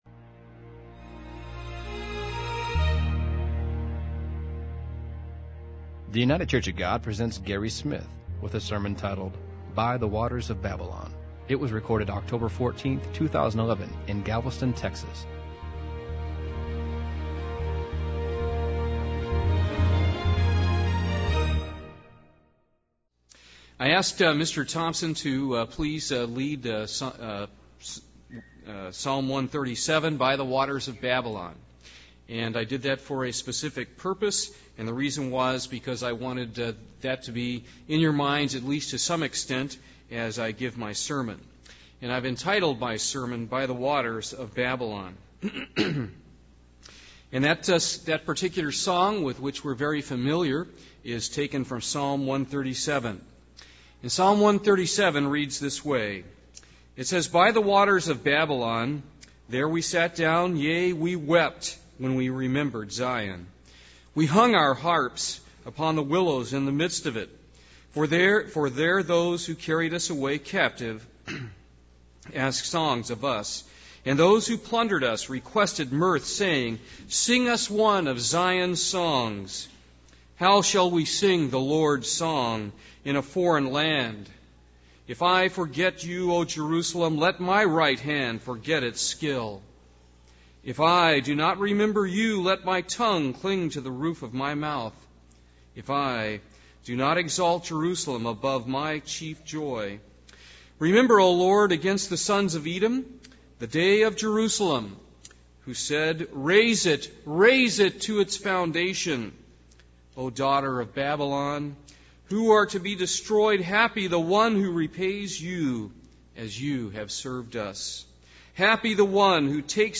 This sermon was given at the Galveston, Texas 2011 Feast site.